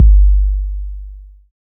808 DEEPK P.wav